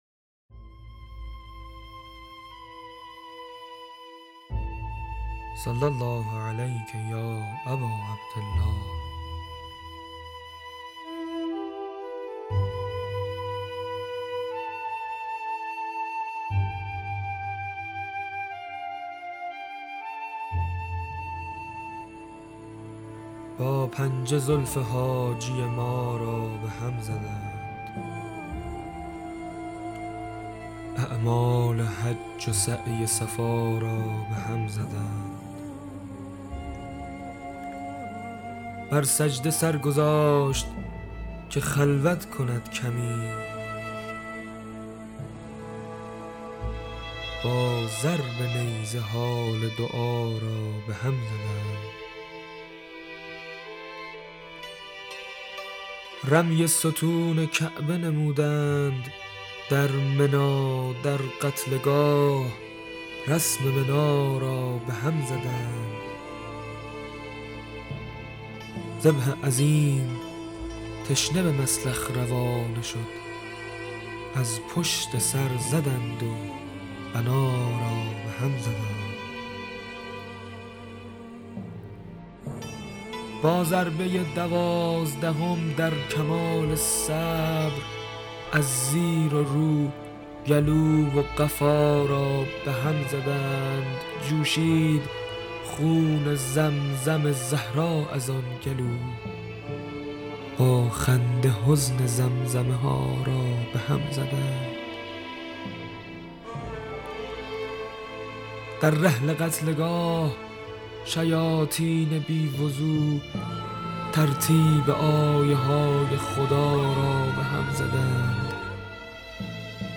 سرویس شعر آئینی عقیق : بسته صوتی طریق اشک مجموعه شعر خوانی تعدادی از شاعران اهل بیت است که به همت استودیو همنوا و با حمایت خانه ی موسیقی بسیج تهیه شده که در ایام اربعین از رادیو اربعین پخش خواهد شد.
شعرای طریق اشک: